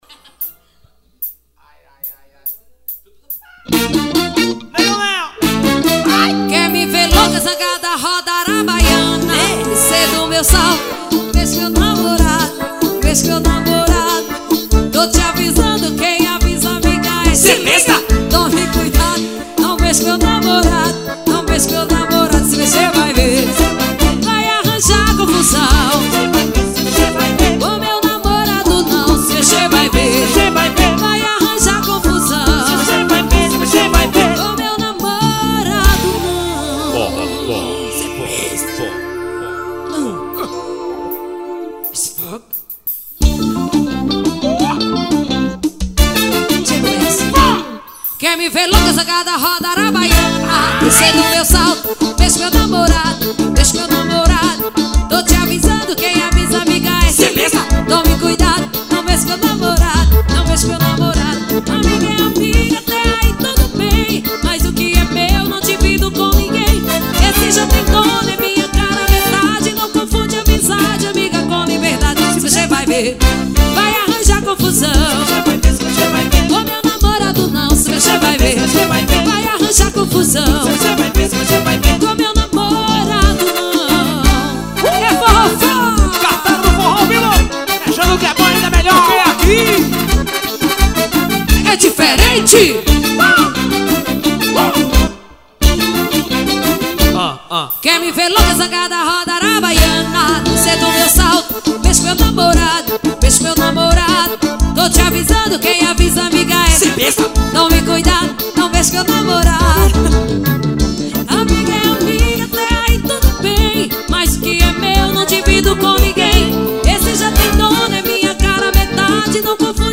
forró fó.